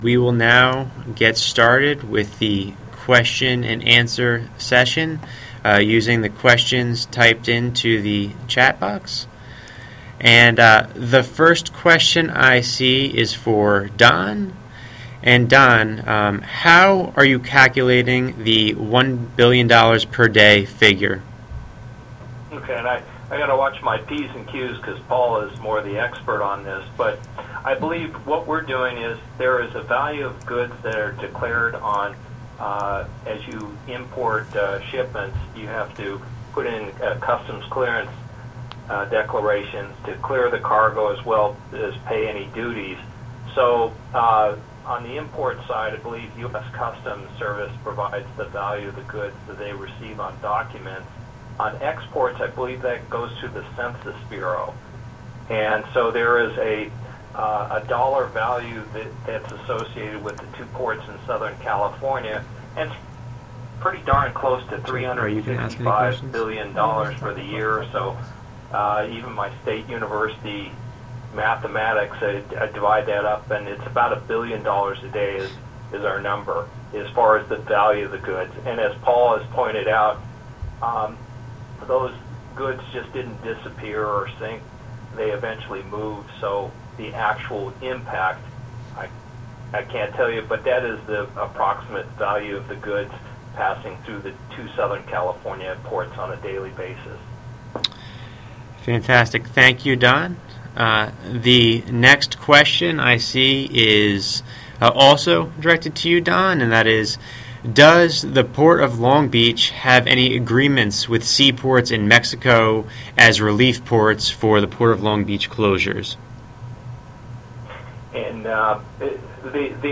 Note: Due to technical difficulties, the first few minutes of audio from this seminar were not captured in the audio recording.